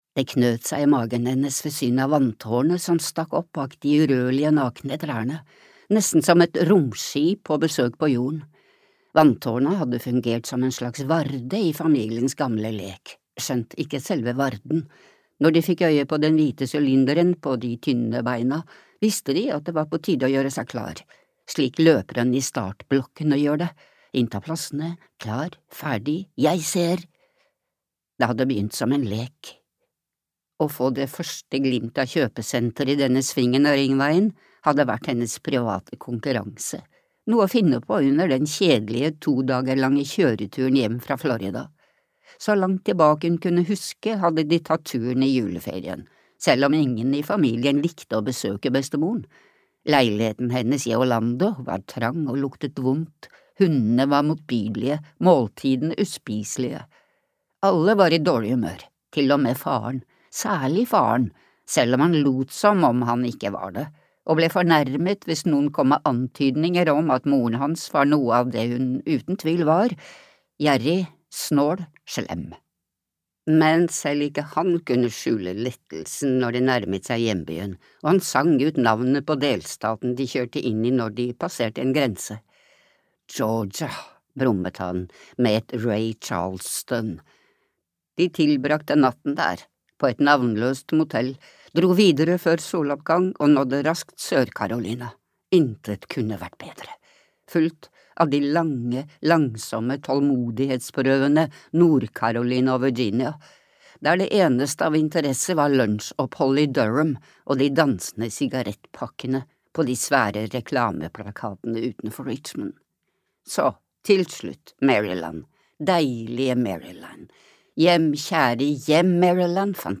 De døde vet (lydbok) av Laura Lippman